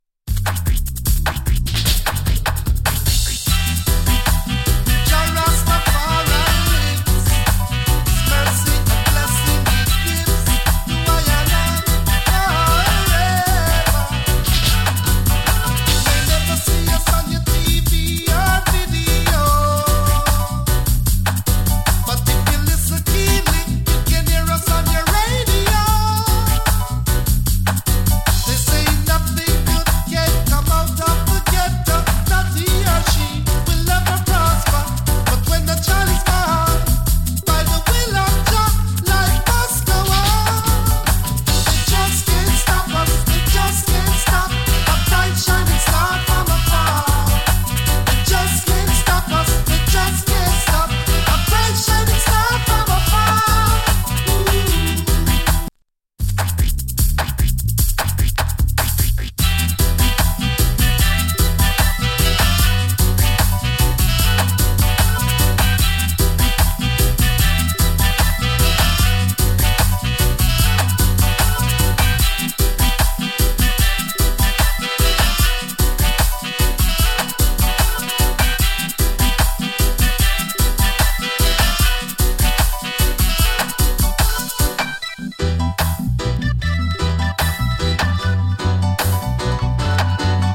STEPPER NEW ROOTS STYLE ! ＋ VERSION.